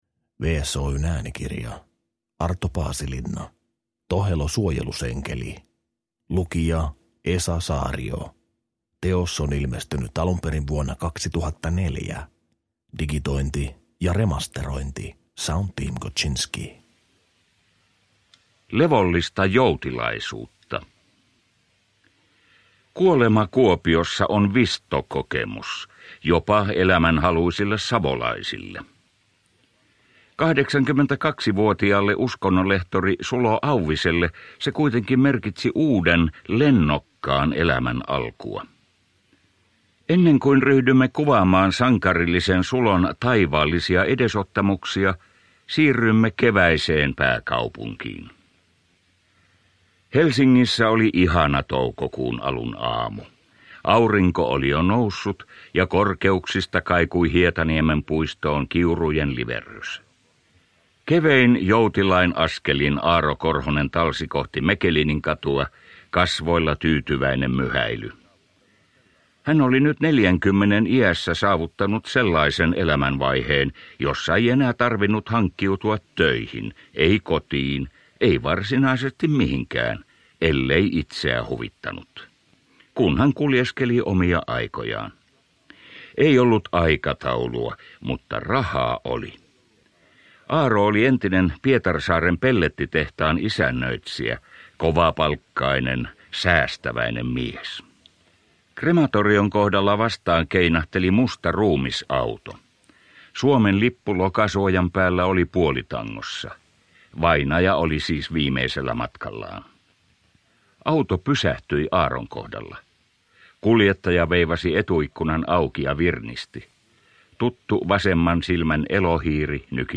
Tohelo suojelusenkeli – Ljudbok – Laddas ner